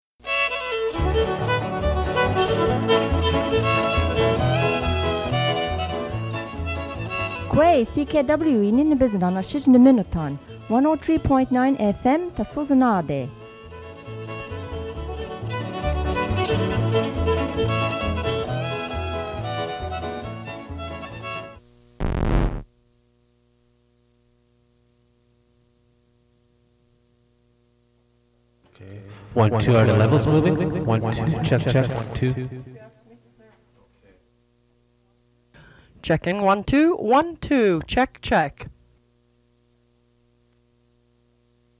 Radio jingles